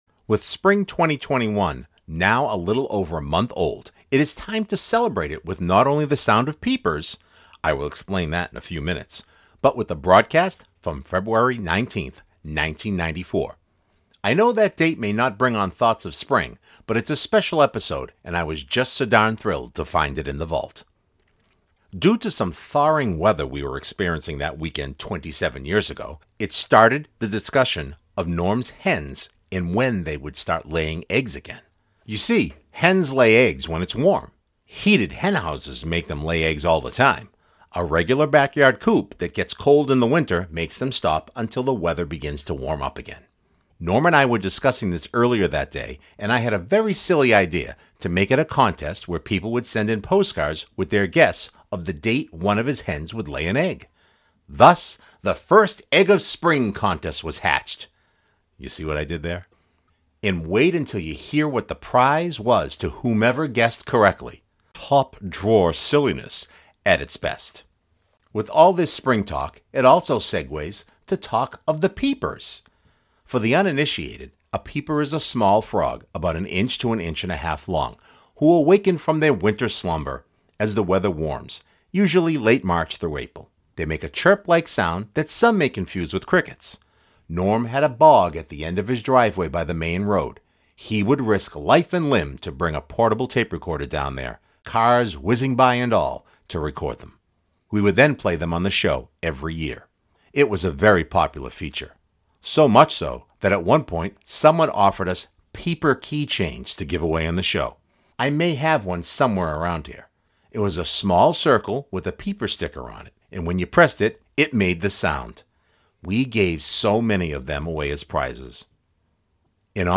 With Spring 2021 now a little over a month old it is time to celebrate it with not only the sound of peepers (I will explain that in a few minutes) but with a broadcast from February 19th, 1994.